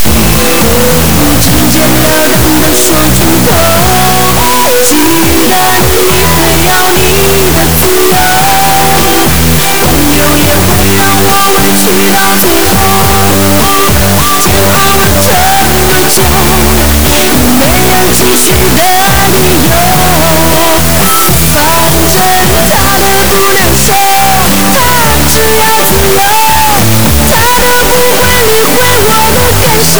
DM368改用TLV320AIC23B芯片时录音有很多杂音
我的录音采样的双声道，48kHz，16bit，芯片用的12.288MHz的晶振，linux驱动里默认的12MHz，我认为是我的驱动设置可能有问题
附件是录的声音